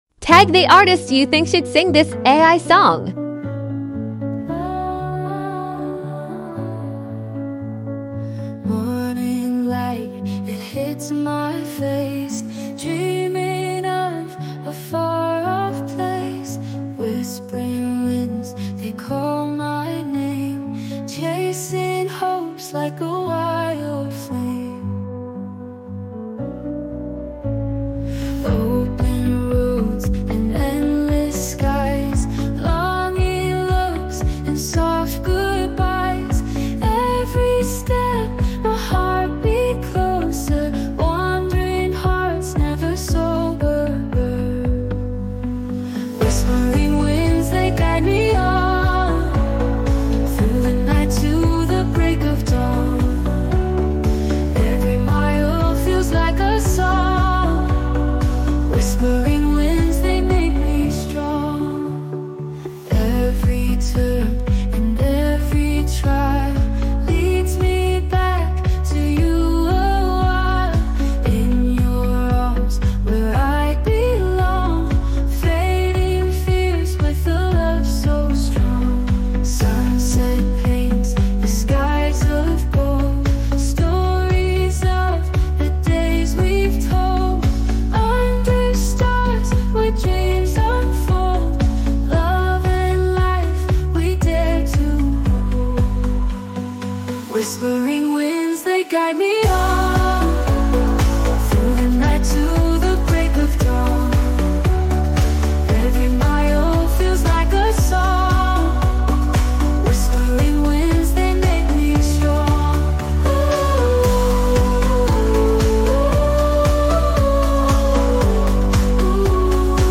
ai generated 50s songs